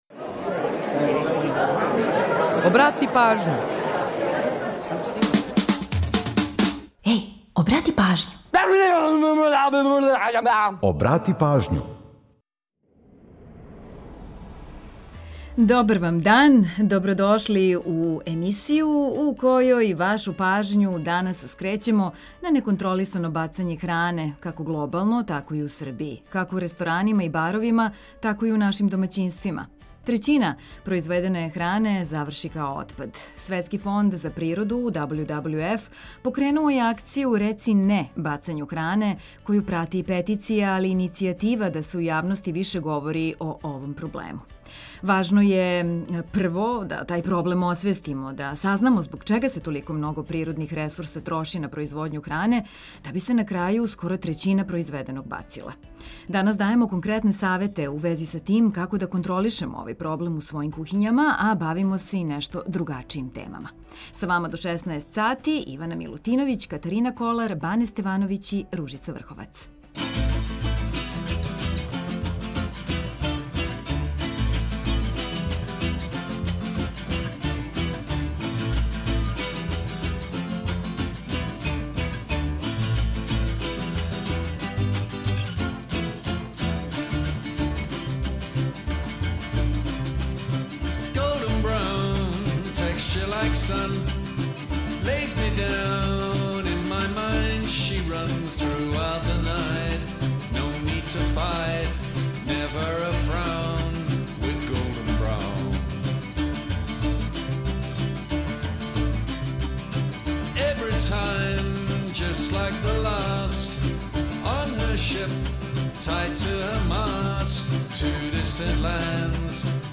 У данашњој емисији, стручњаци из Фонда за природу WWF дају савете како смањити бацање хране. У наставку емисије, бавимо се мало другачијим темама, музиком пре свега.